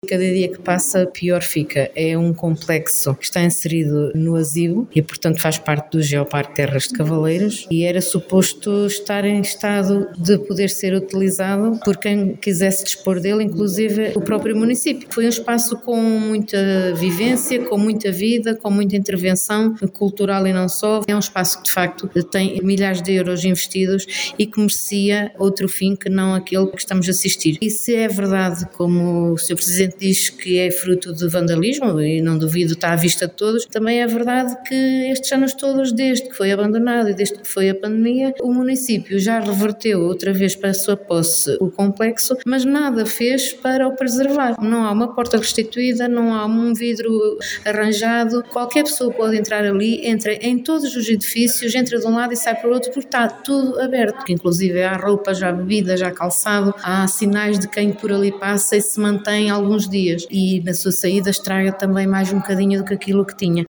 A situação foi denunciada em Reunião de Câmara pela vereadora do PSD Clementina Gemelgo, depois de uma visita ao local: